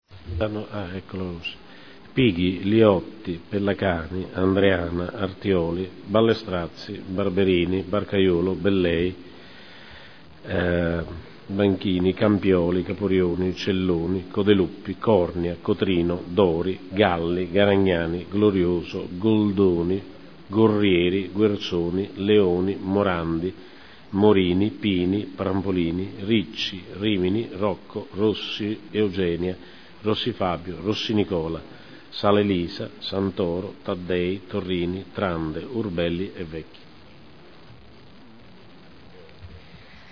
Seduta del 13/12/2010 Appello